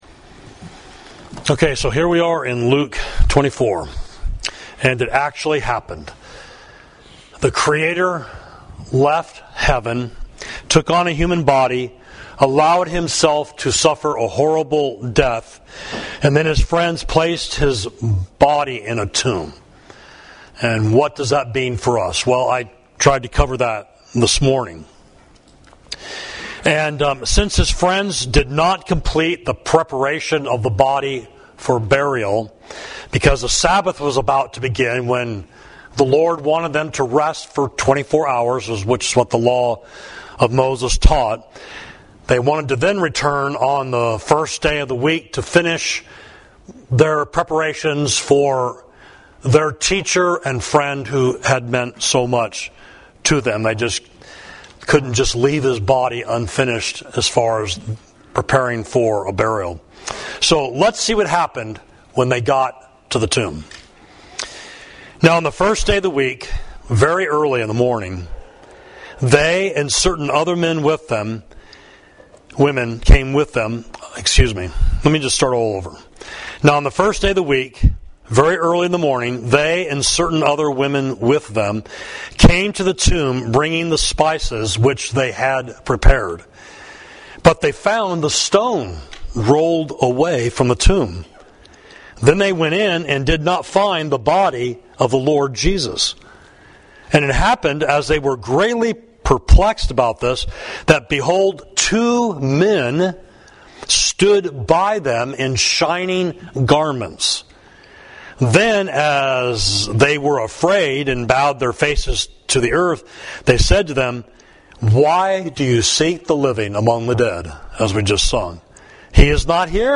Sermon: Slow of Heart to Believe, Luke 24.1–27